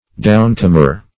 Downcomer \Down"com`er\, n.